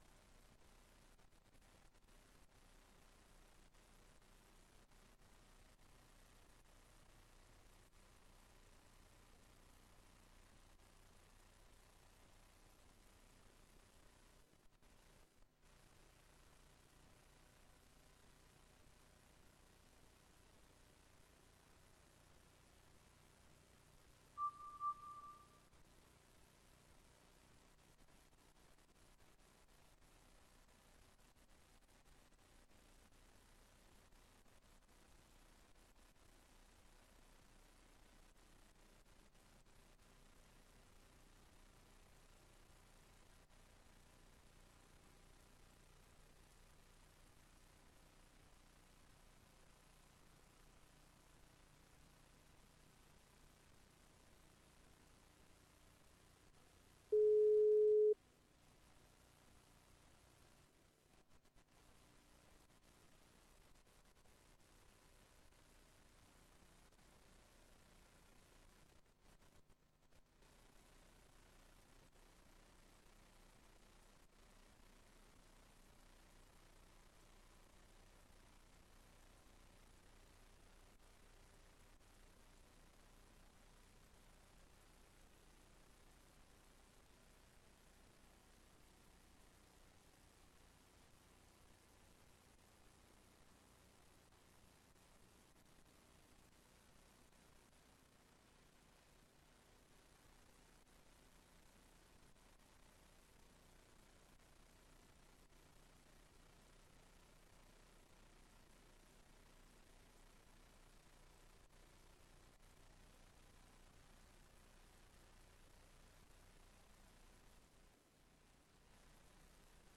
Raadsbijeenkomst 07 oktober 2025 19:30:00, Gemeente Tynaarlo
Locatie: Raadszaal